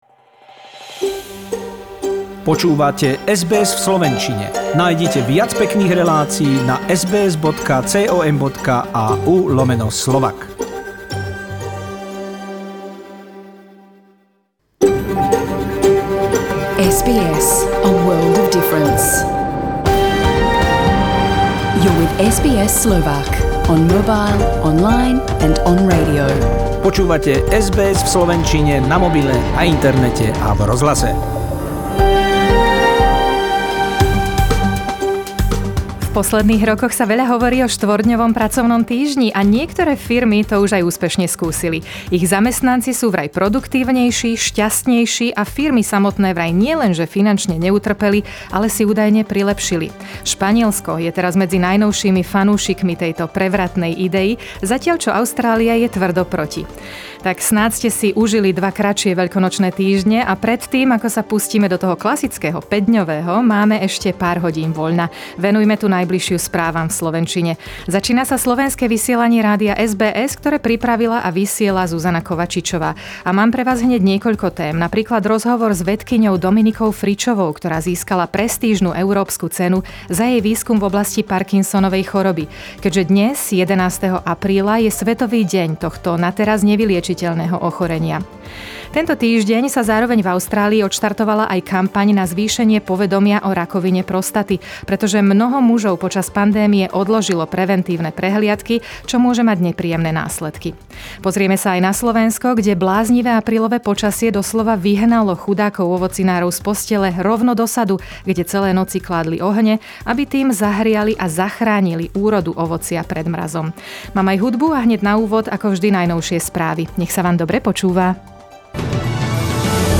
News bulletin by SBS NACA